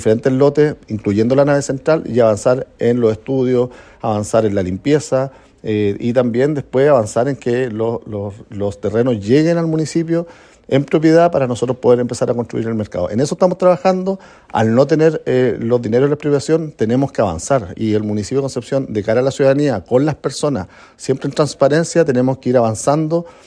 El alcalde Héctor Muñoz indicó que buscarán “avanzar en los estudios, en la limpieza, y también después avanzar en que los terrenos lleguen al municipio en propiedad, para nosotros poder empezar a construir el mercado.